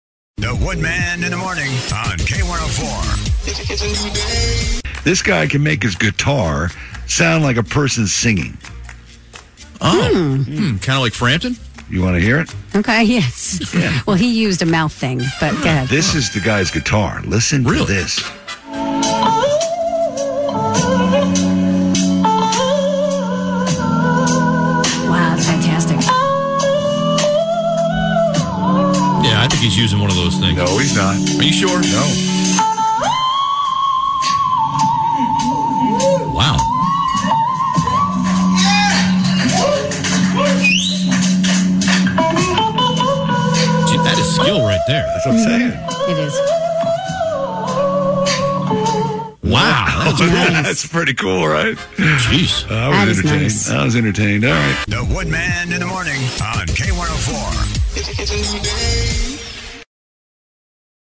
guitarsinging